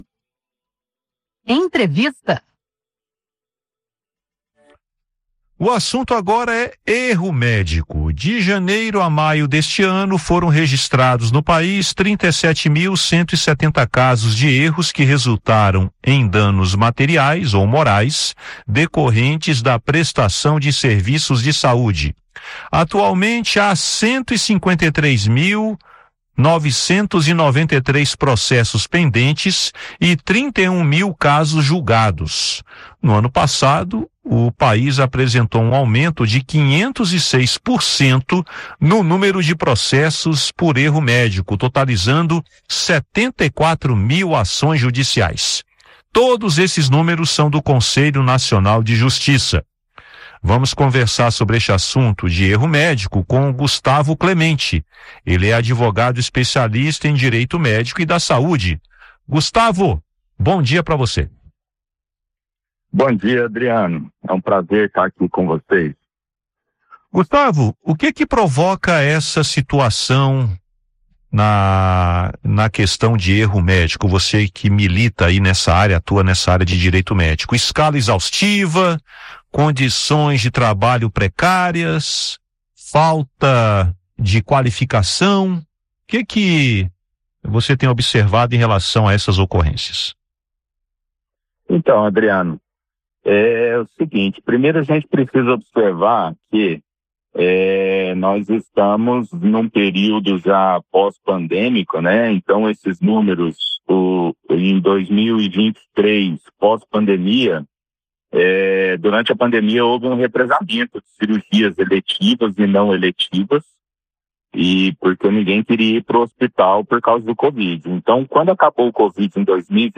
Em entrevista à Rádio Senado